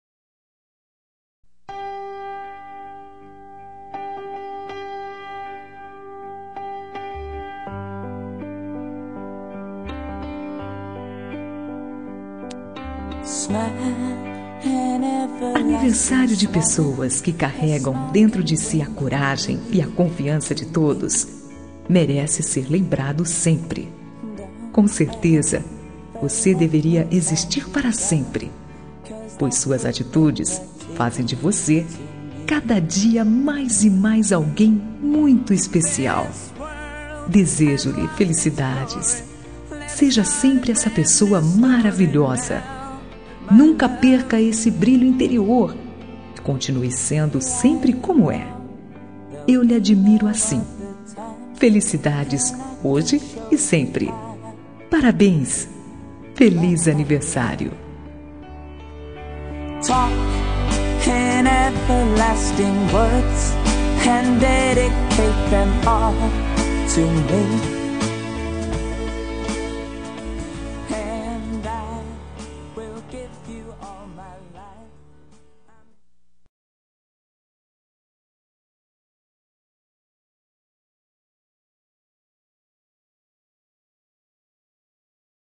Aniversário de Pessoa Especial – Voz Feminina – Cód: 1886